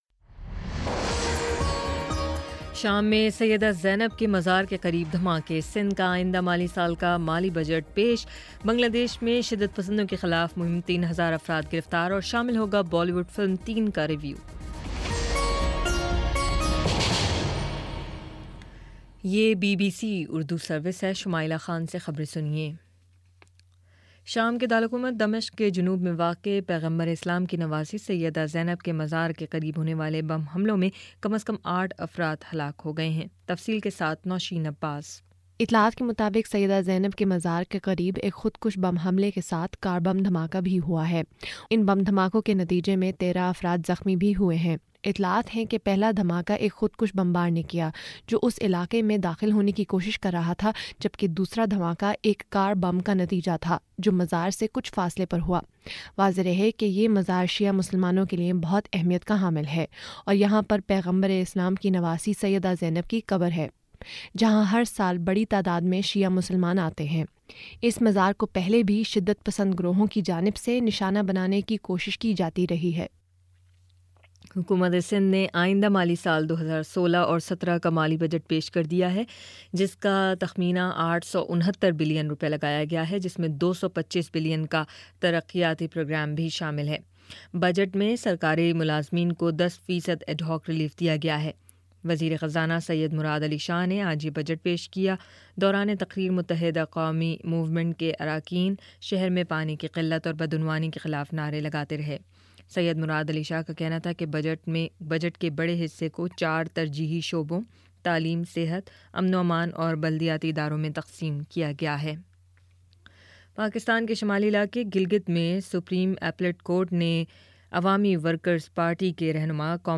جون 11 : شام سات بجے کا نیوز بُلیٹن